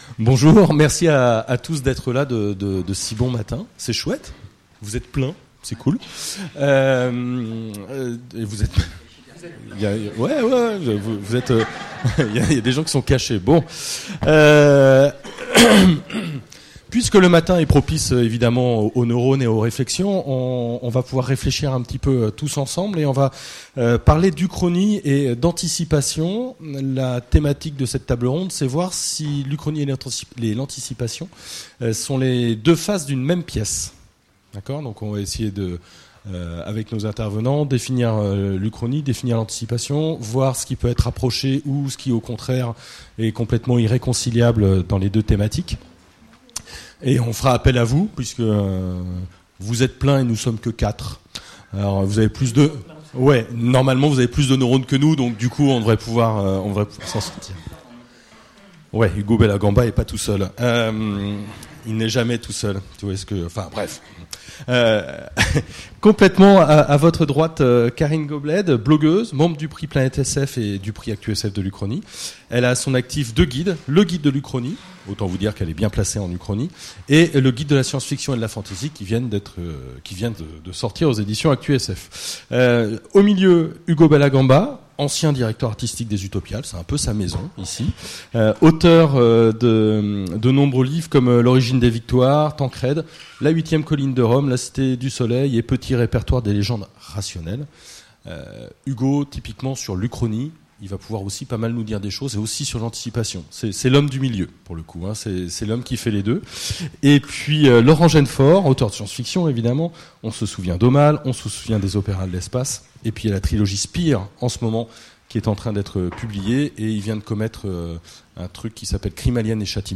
Utopiales 2017 : Conférence Uchronie vs Anticipation